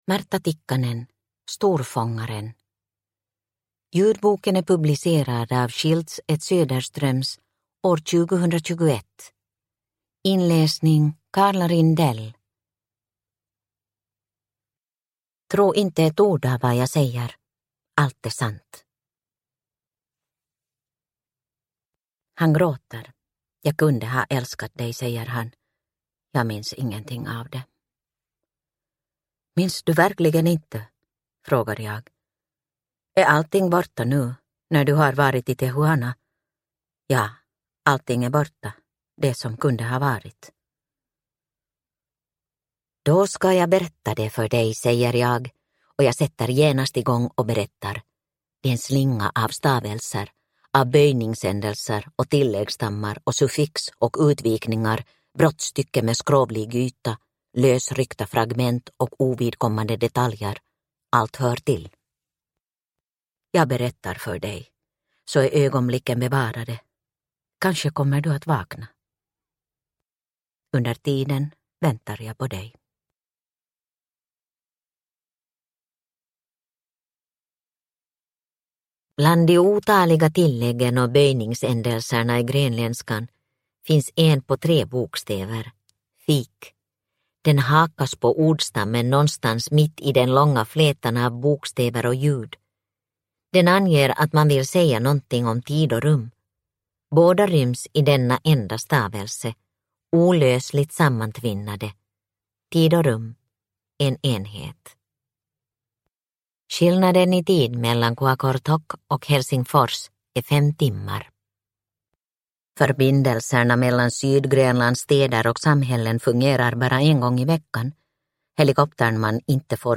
Storfångaren – Ljudbok – Laddas ner